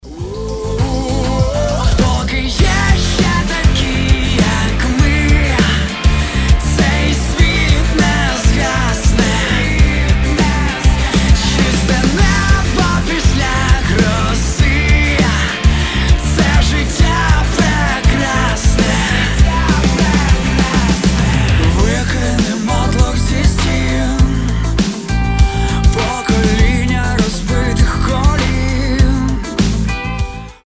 • Качество: 320, Stereo
Alternative Rock
Pop Rock
украинский рок